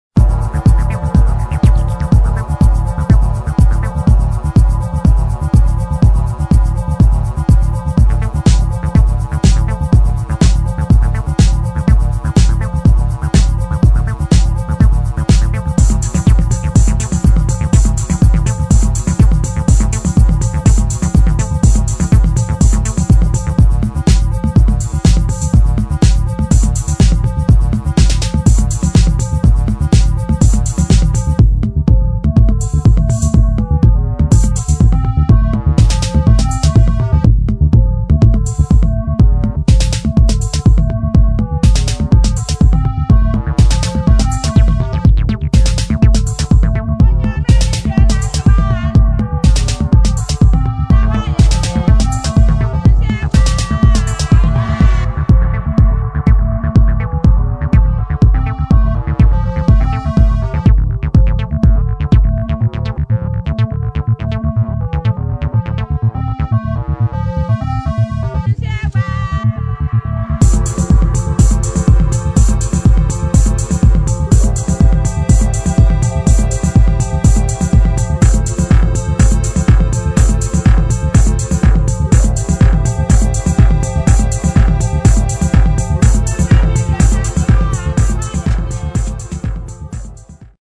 [ DEEP HOUSE / ACID / TECHNO ]